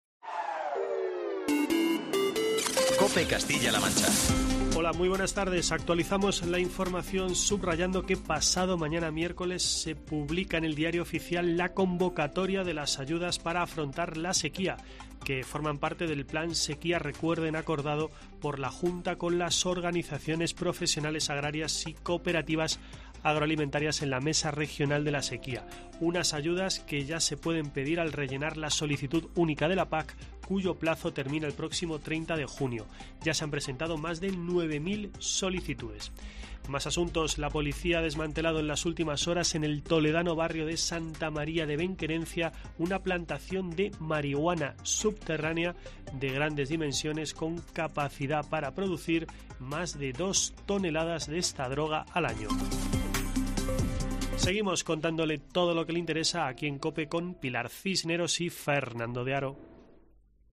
boletín informativo de COPE Castilla-La Mancha de las 16:00 horas de este lunes, 5 de junio de 2023.